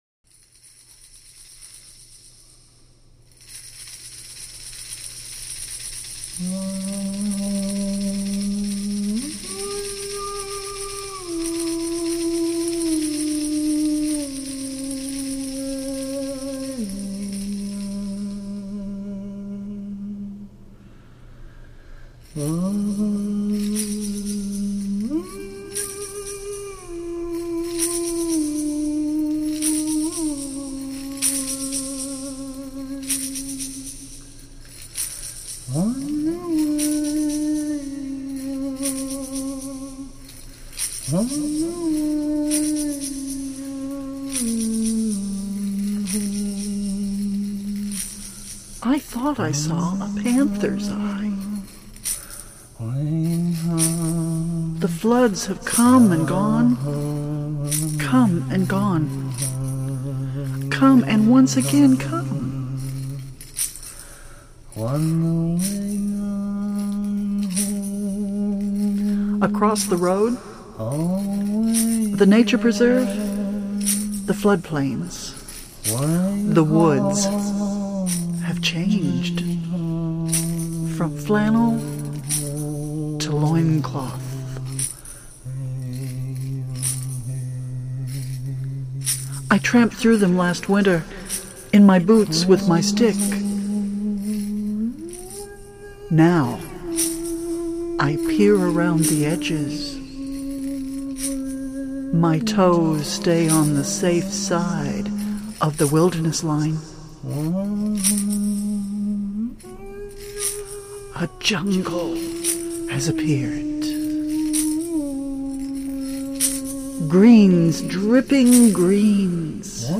native american flute and drumming
flute and drum